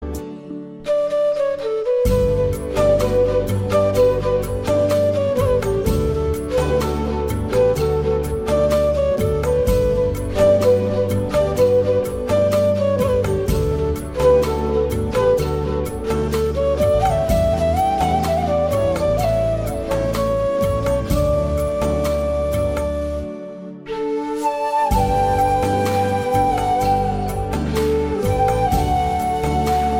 flute